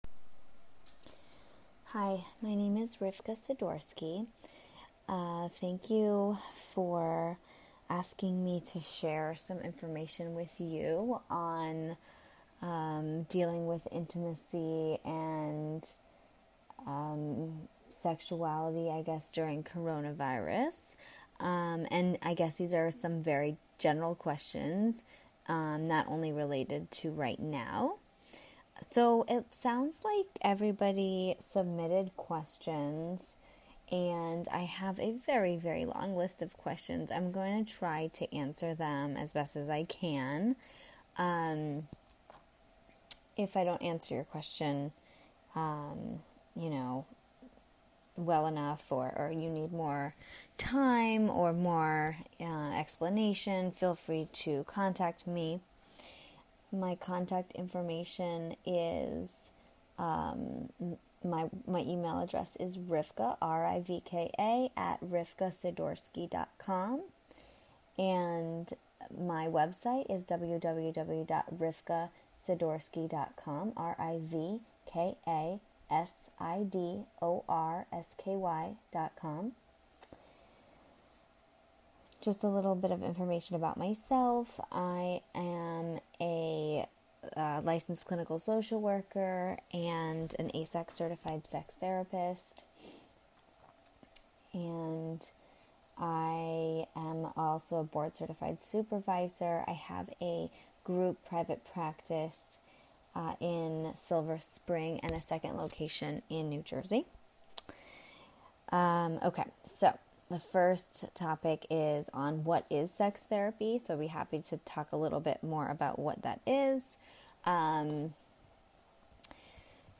Insightful talk